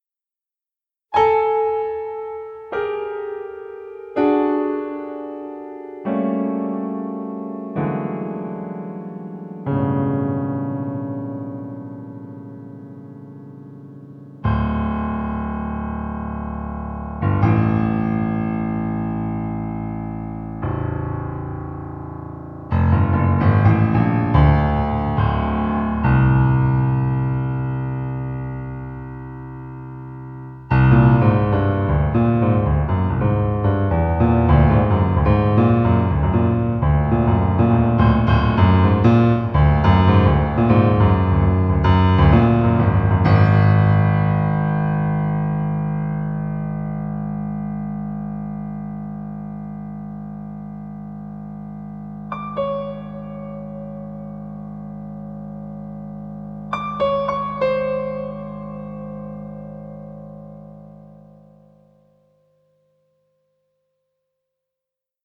solo album